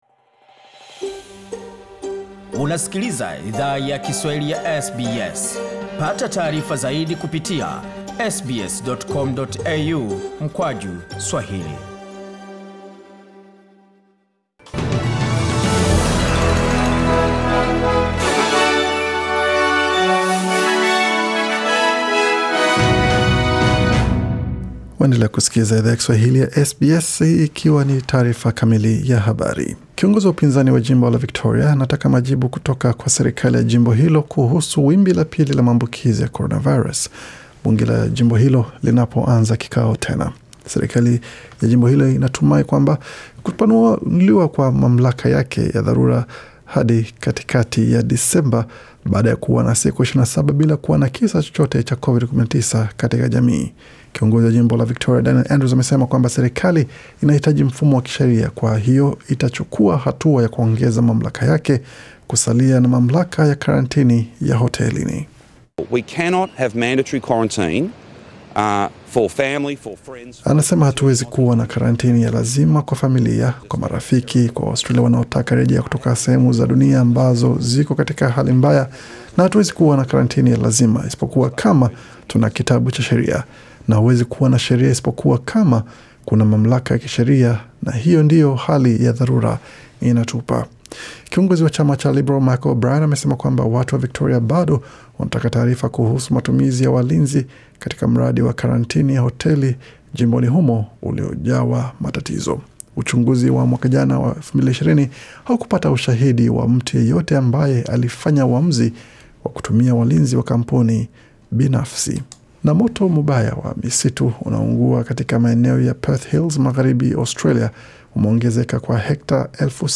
Taarifa ya habari 2 Januari 2021